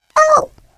Turret_turret_collide_5.wav